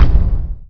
step1.wav